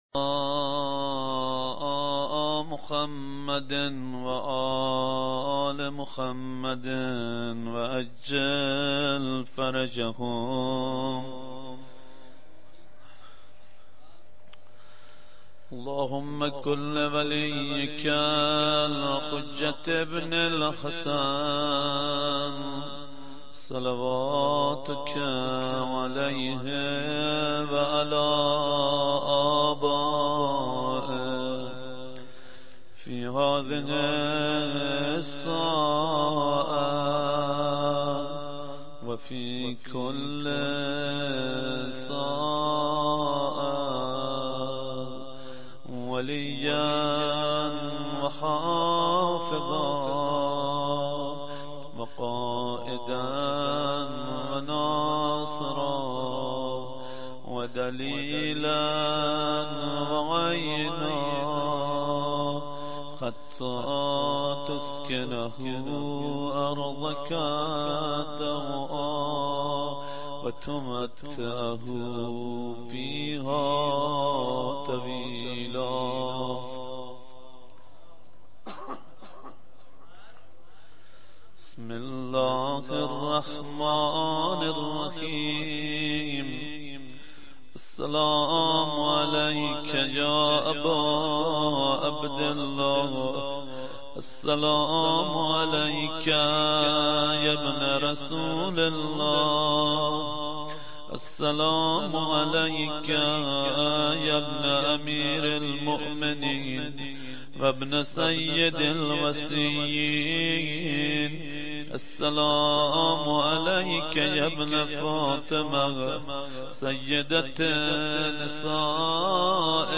خیمه گاه - هیئت مهدیه احمد آباد - زیارت عاشورا-شب هفتم محرم97-مهدیه احمدآباد